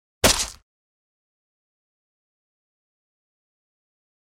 SFX被刀砍在肉上砍出血的声音音效下载
SFX音效